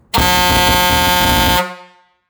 Nedking 65cm Lufthörner aus Edelstahl - Zughörner Niederlande
Anzahl der dBs: 120 dB